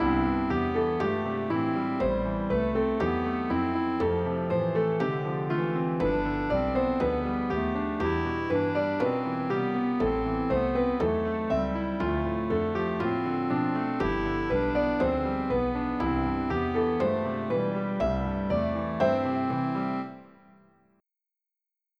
Add a walking bass line on a new track following the chord progression of the piano part.
Result: Walking bass line following the chord progression on a new track
piano_accompaniment.wav